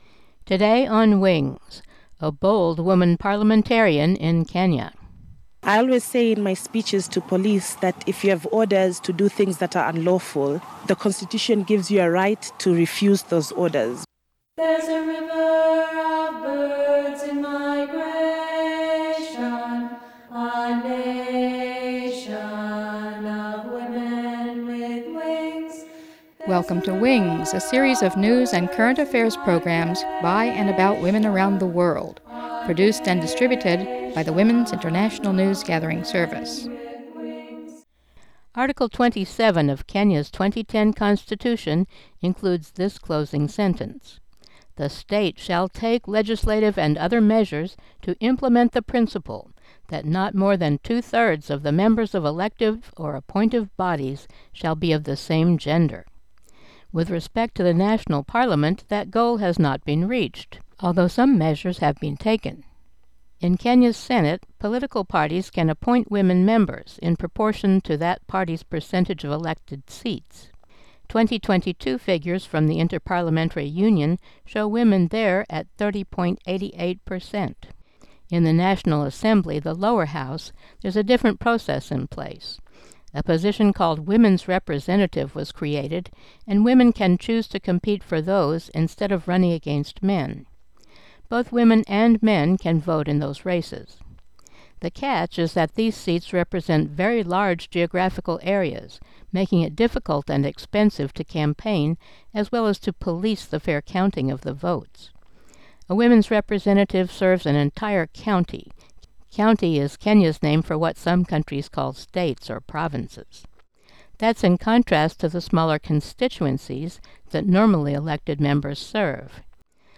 File Information Listen (h:mm:ss) 0:28:46 WINGS12-22KenyaPolitics-28_46-320kbps.mp3 Download (19) WINGS12-22KenyaPolitics-28_46-320kbps.mp3 69,041k 320kbps Mono Comments: Featured speakers Zuleikha Juma Hassan, Women's Representative in the National Assembly representing Kwale County; Kenya's President Uhuru Kenyatta.